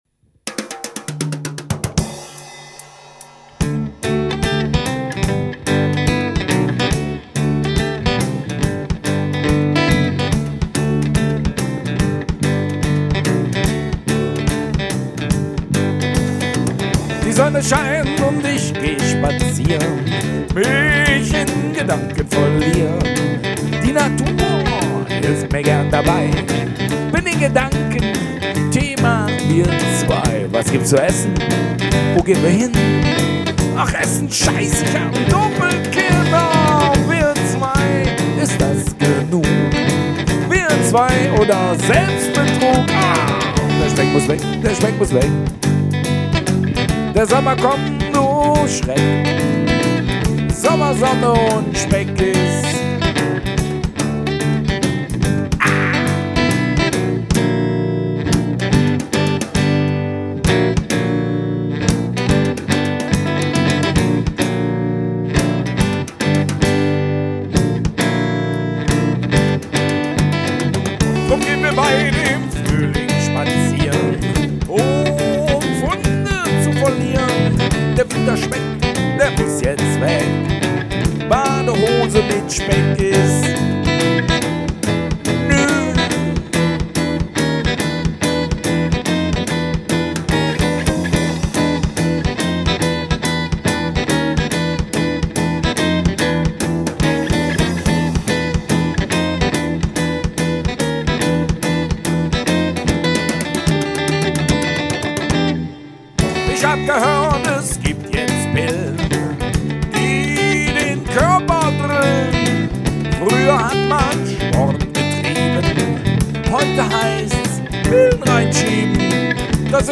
Unplugged Set - live aufgenommen im Studio